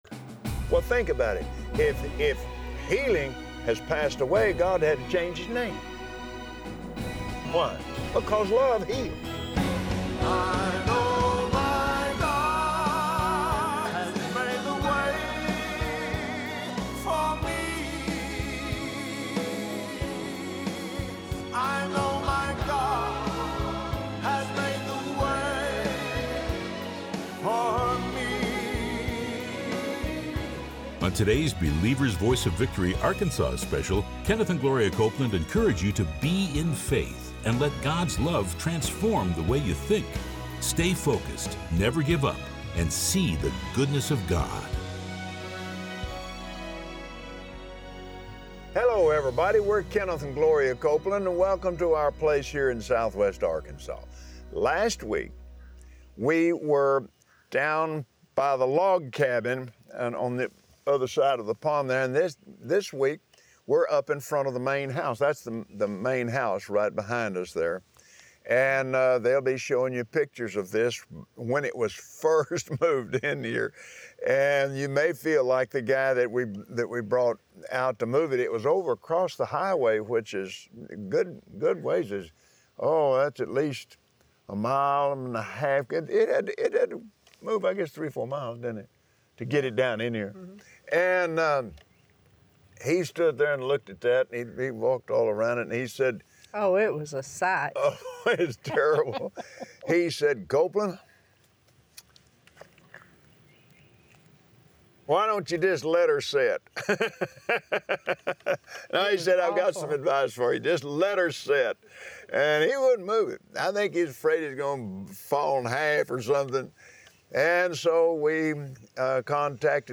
Believers Voice of Victory Audio Broadcast for Monday 06/26/2017 God is Love, and Love is an unending source of everything you need for victory. Watch Kenneth and Gloria Copeland on Believer’s Voice of Victory as they share how developing a true knowledge of God’s love is the key that unlocks blessing.